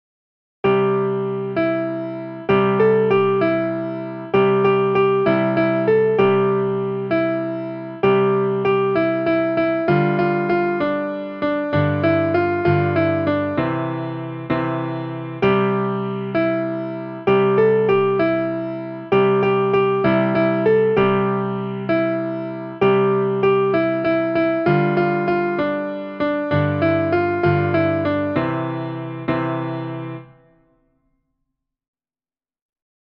traditional Nursery Rhyme for children
piano